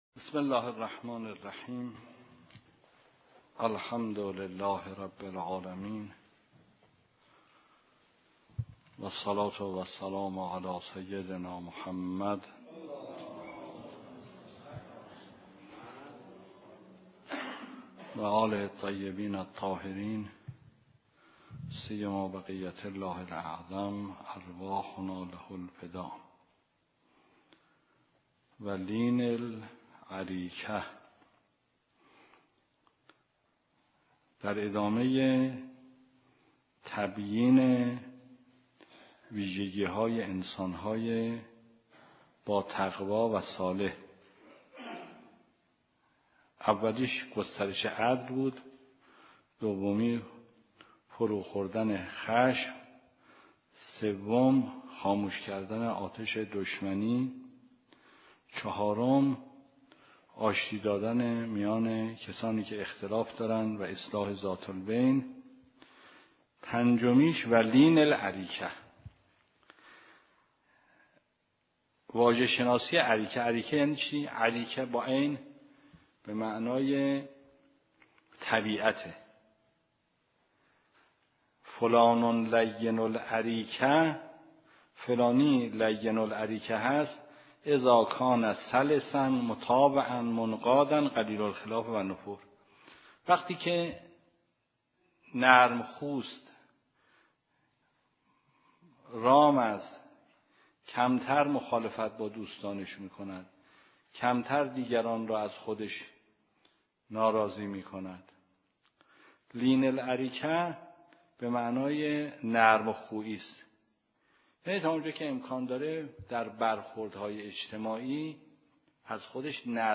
درس خارج فقه مبحث حج